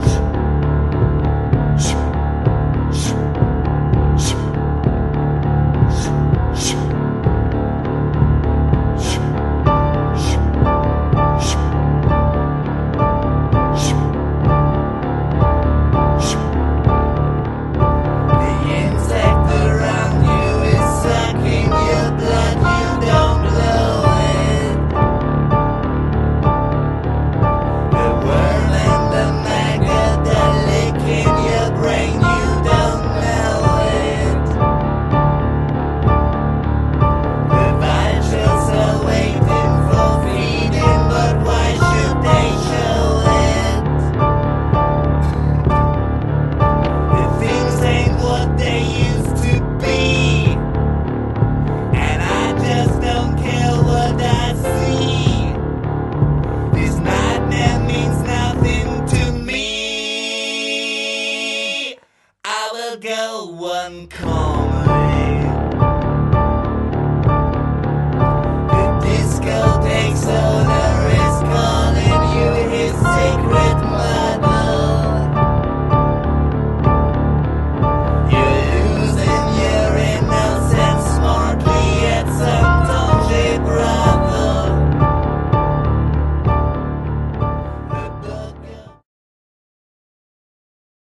ld-voc., p., org., perc.
voc., bg., handclapping
voc., perc.